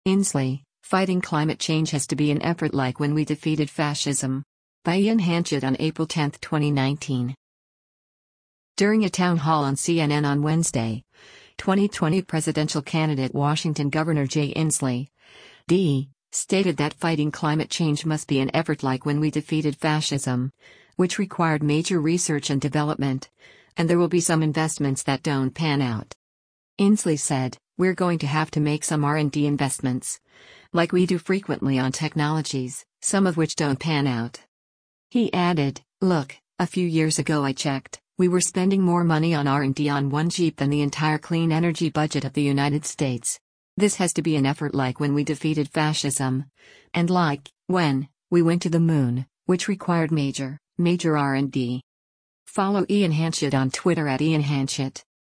During a town hall on CNN on Wednesday, 2020 presidential candidate Washington Governor Jay Inslee (D) stated that fighting climate change must be an effort “like when we defeated fascism,” which required “major” research and development, and there will be some investments that “don’t pan out.”